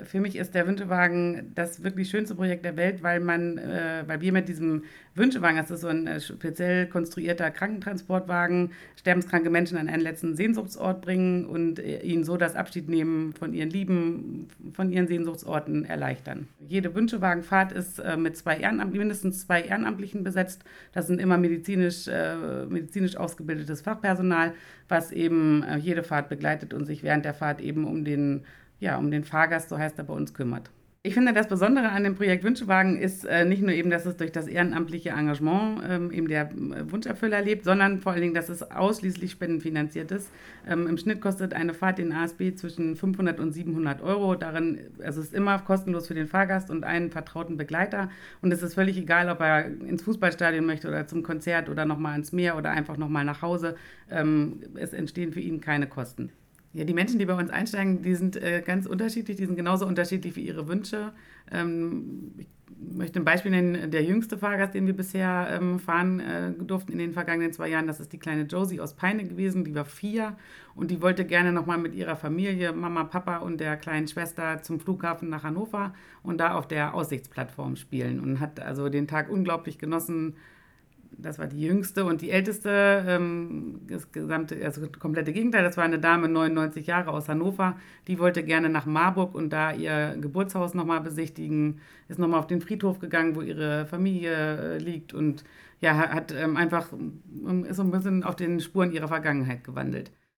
Im Interview erzählt er von dem Ablauf der Wunschfahrten, wie man mit der psychischen Belastung umgeht und von seiner emotionalsten Fahrt.